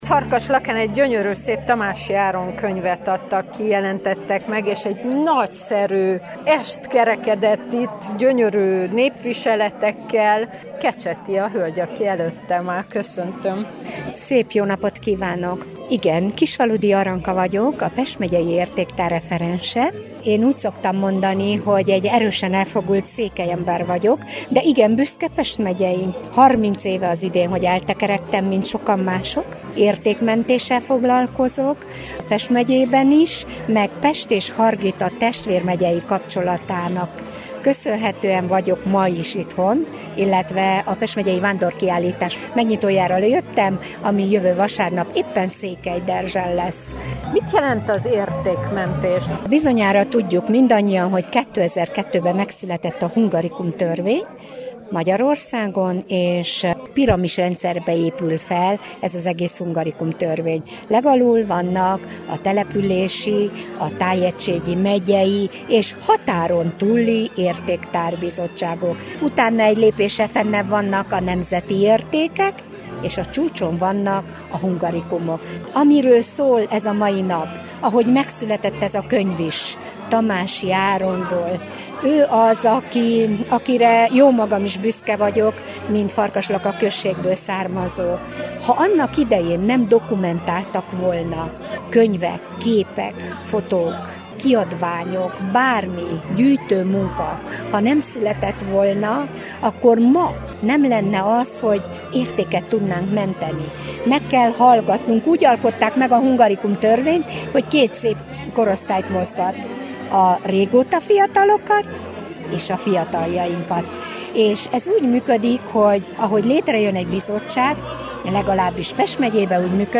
értékmentővel beszélgetett.